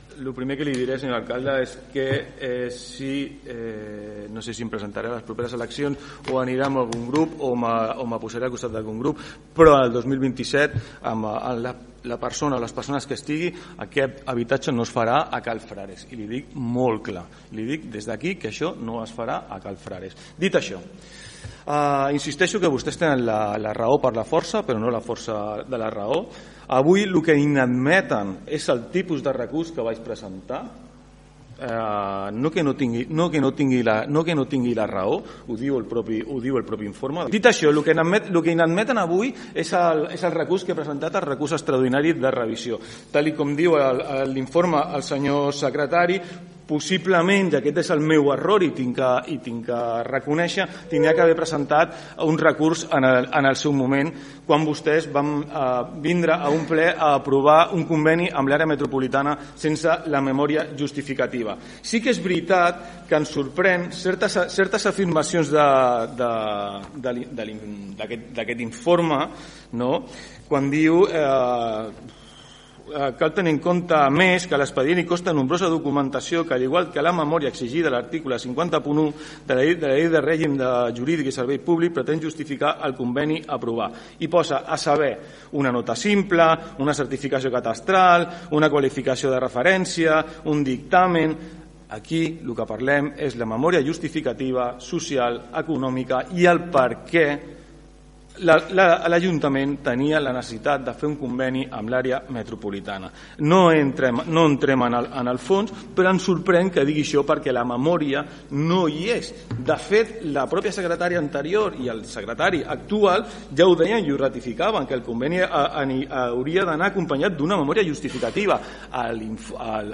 Davant aquesta inadmissió, el regidor no adscrit ha anunciat que es plantejaria la viabilitat d’interposar un recurs contenciós administratiu: